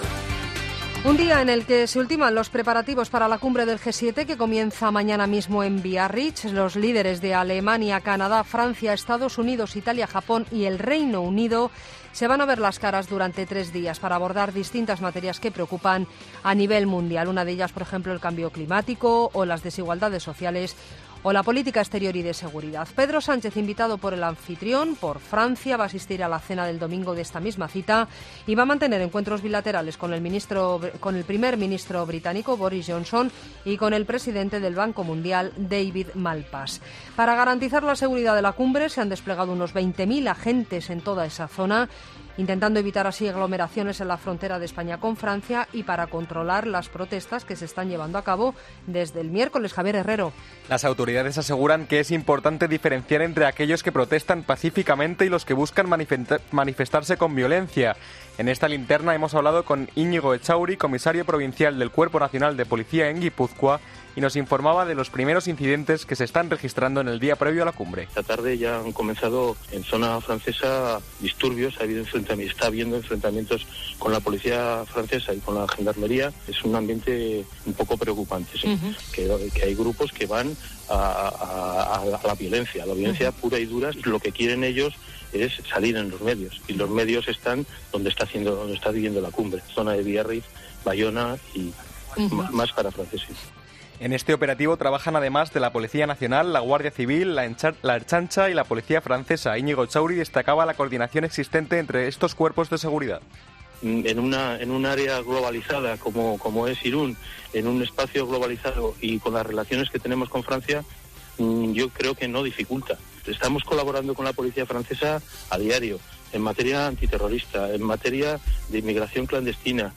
Boletín de noticias de COPE del 23 de agosto de 2019 a las 23.00 horas